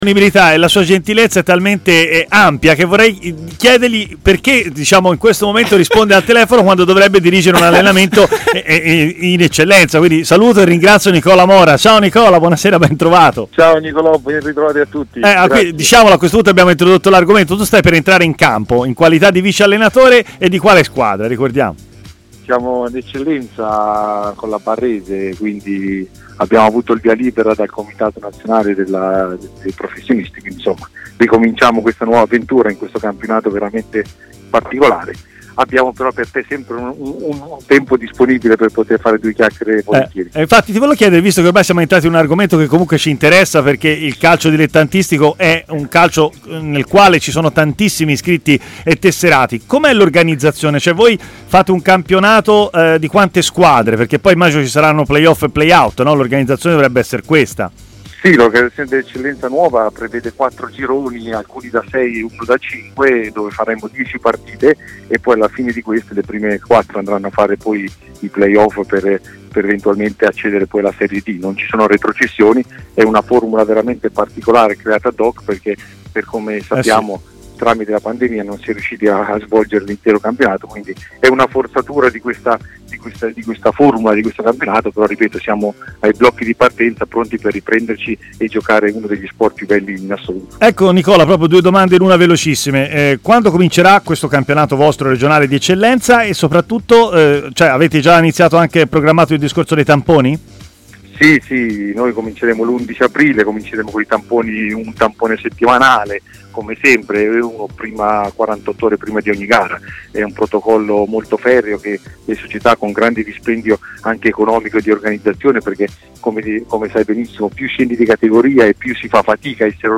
è intervenuto in diretta a TMW Radio